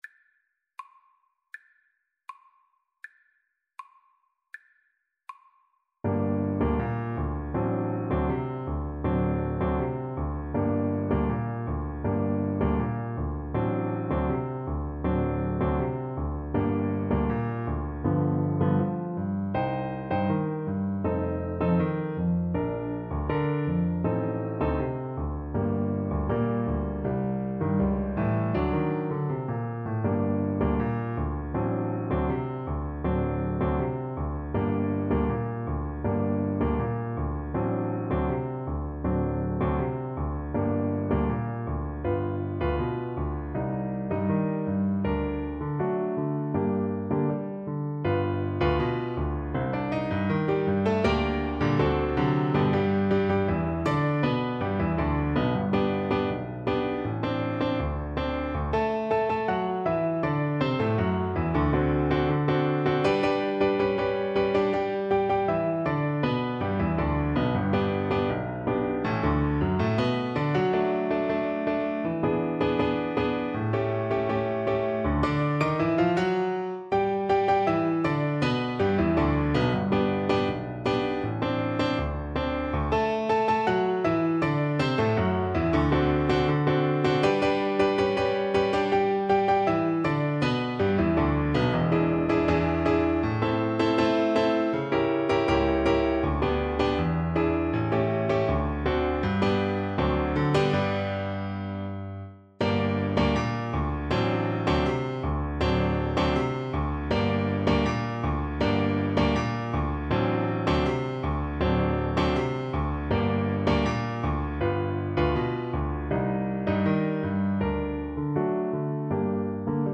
A beautiful tango melody in an A-B-A form.
2/4 (View more 2/4 Music)
Grazioso =80
Arrangement for Clarinet and Piano